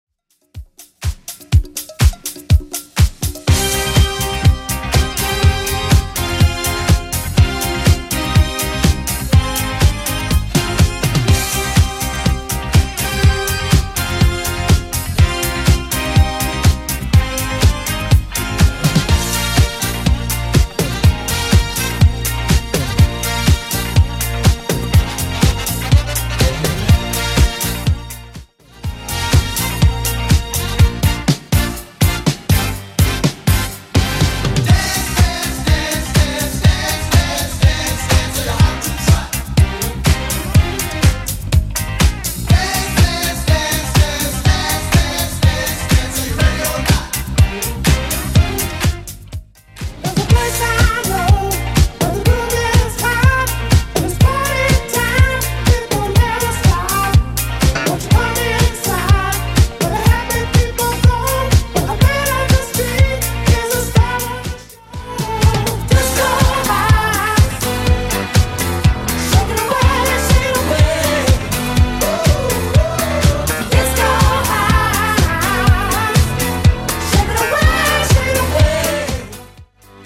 BPM: 123 Time